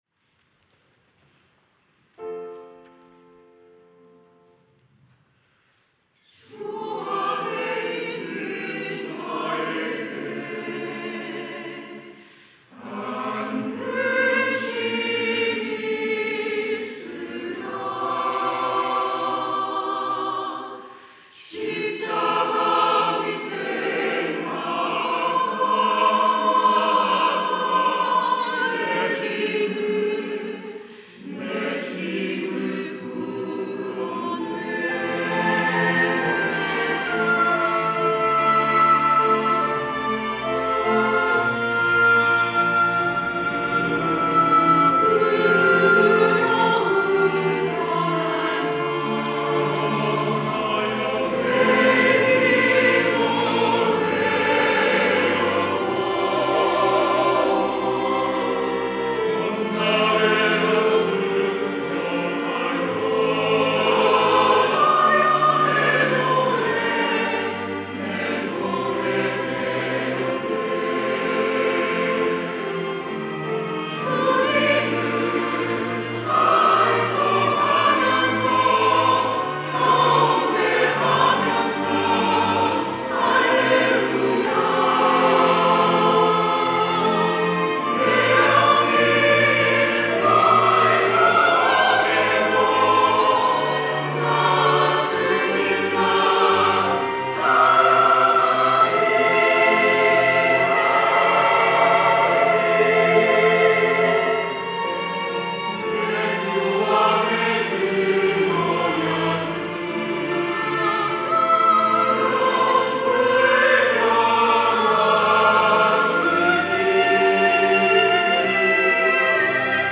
호산나(주일3부) - 주 안에 있는 나에게
찬양대